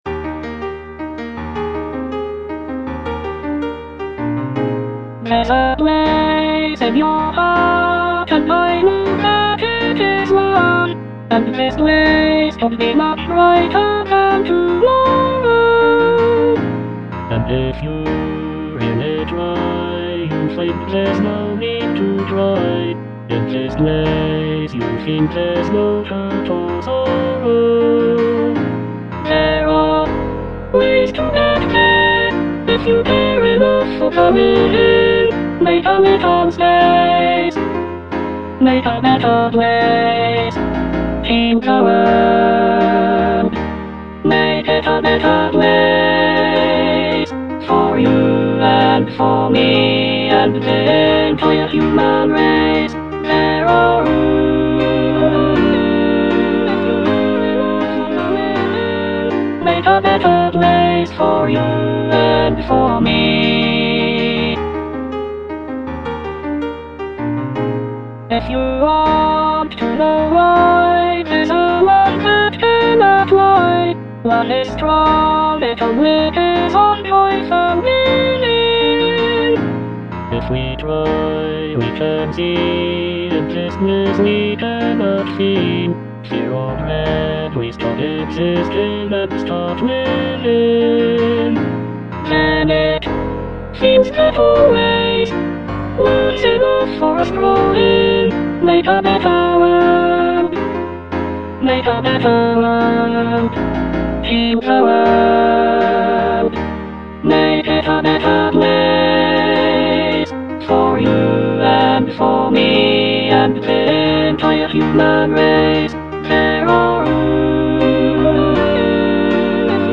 Alto II (Emphasised voice and other voices)